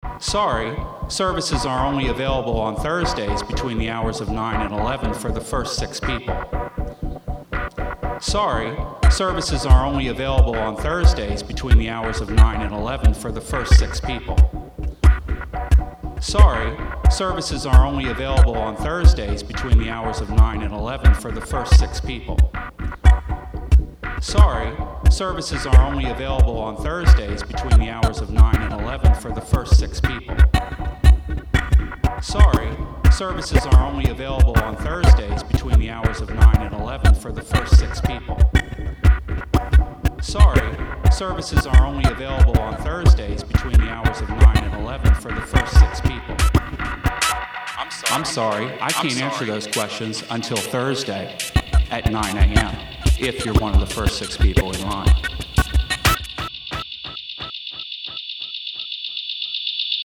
Ultra-red asks fifteen artists and activists to come up with a one-minute audio recording in response to the question: What is the sound of the war on the poor? The results range from field recordings, re-appropriated sounds, mini-symphonies and spoken rants.